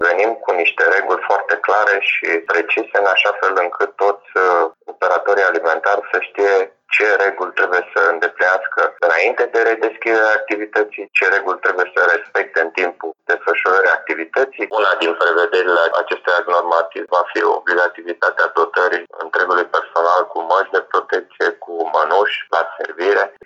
• Alexandru Duma, șeful Direcției Control Oficial din cadrul Autorității Naționale Sanitare Veterinare